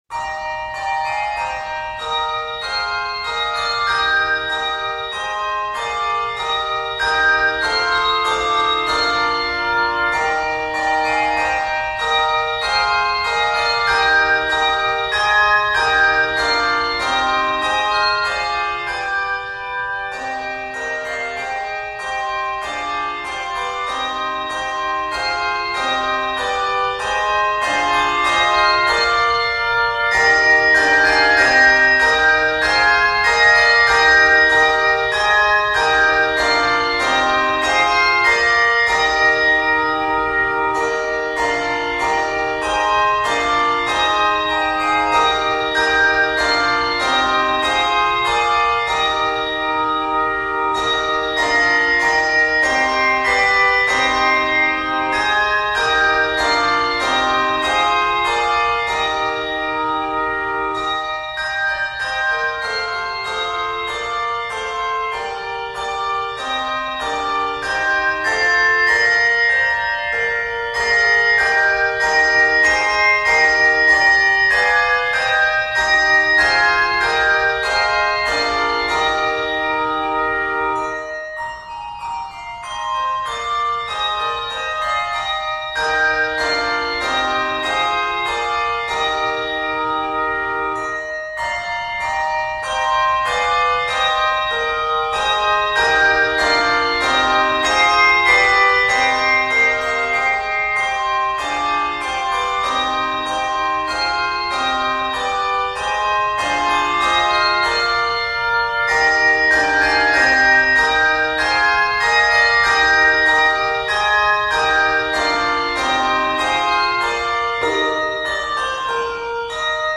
Arranged in Eb Major throughout, it is 73 measures.
Octaves: 2-3